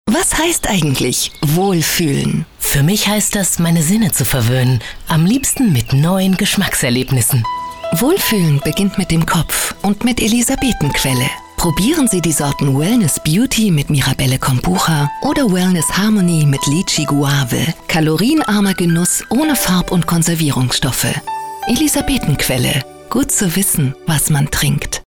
Profi-Sprecherin spanisch.
Sprechprobe: Werbung (Muttersprache):
spanish female voice over artist.